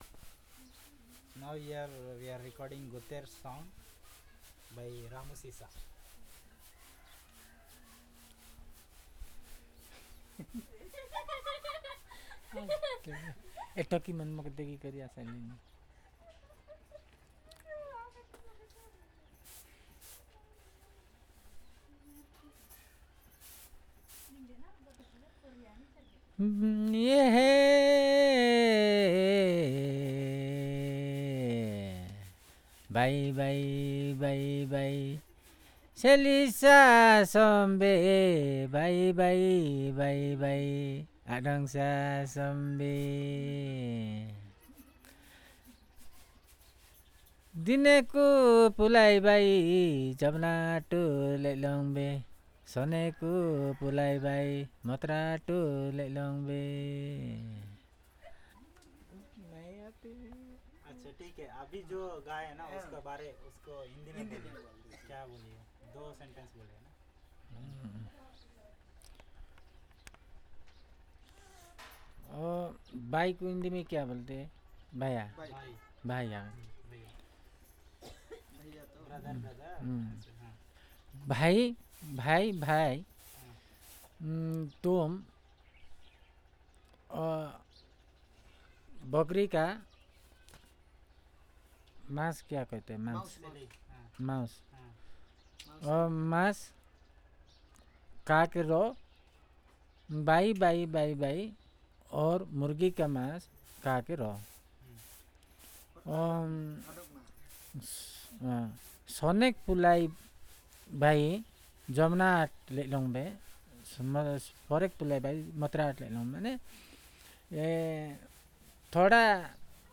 Perfomance of Goter song